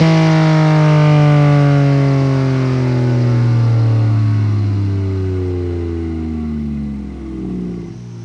rr3-assets/files/.depot/audio/Vehicles/v6_02/v6_02_Decel.wav
v6_02_Decel.wav